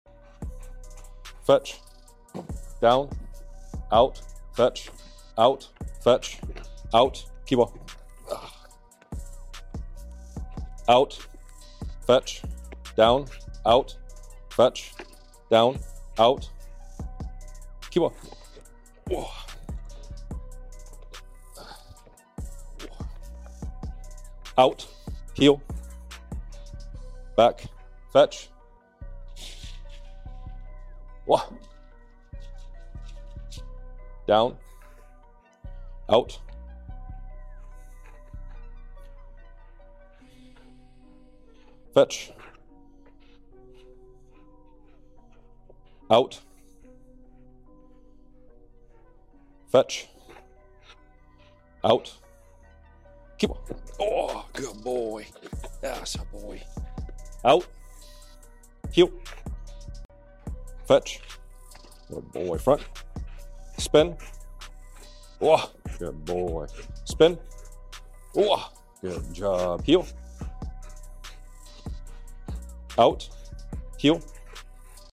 Showing and demonstrating the clarity between picking up an object (Fetch) and biting (Kiwa). In the first part of this video we throw rapid commands. In the second part of the video, we slow everything down.